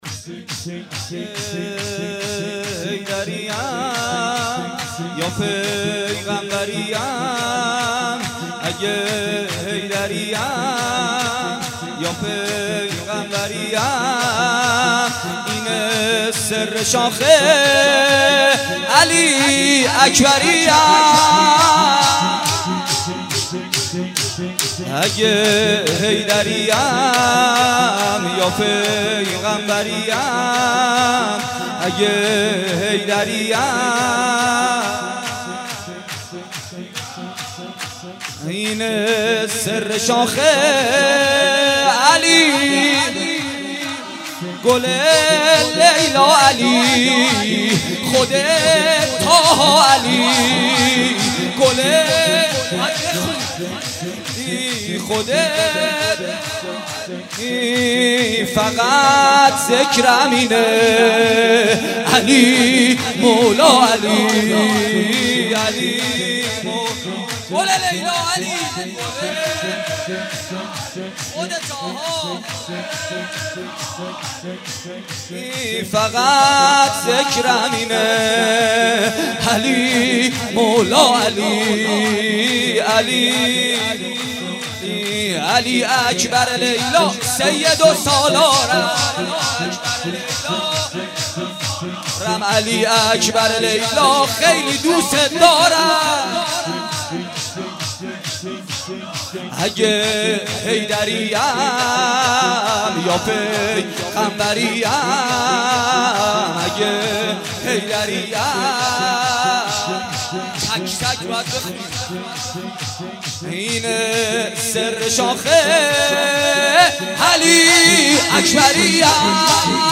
در حسینیه زینبیه شهدای گمنام(علیهم السلام)برگزار شد.